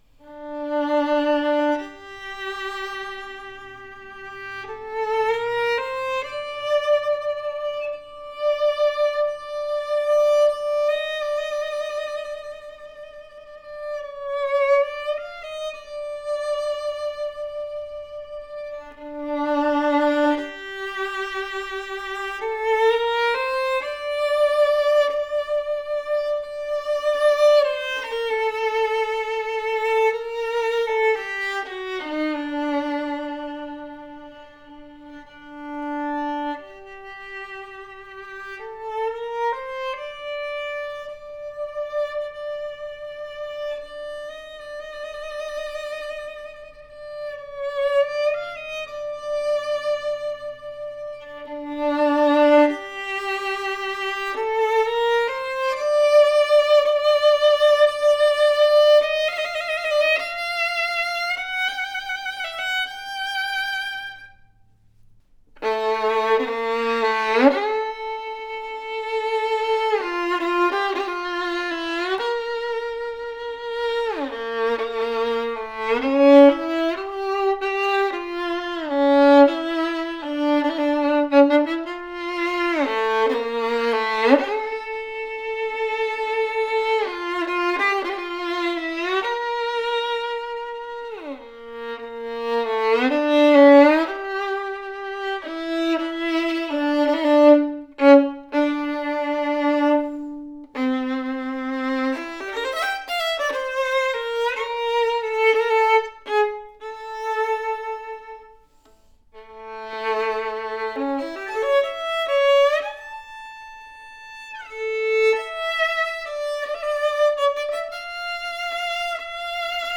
A special edition “Cannone ” violin featuring oil varnish that usually used on our higher end violin, for exquisite antique appearance that also benefits the tone! POWERFULL, dark, projective tone with fantastic projection that carries the tone on distance!
Deep ringing G string, great depth and sings with bold dimension. Sweet and focused mid register that speaks with clarity, clean E string with a singing tone quality.